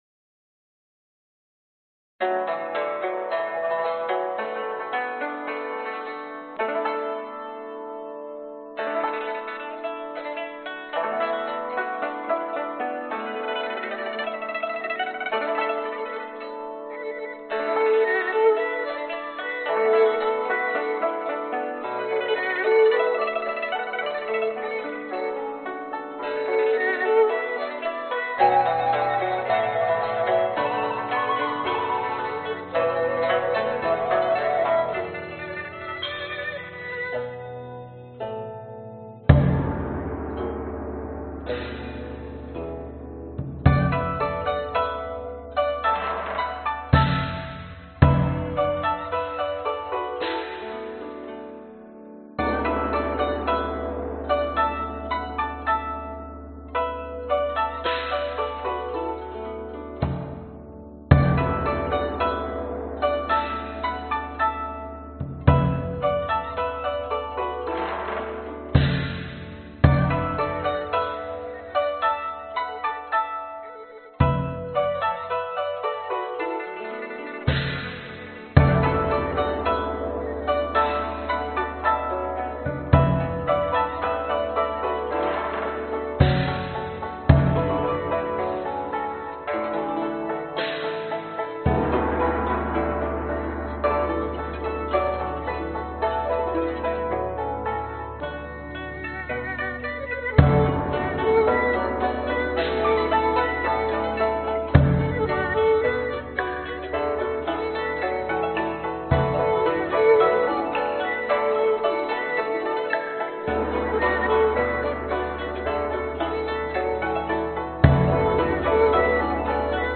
Tag: 循环 原声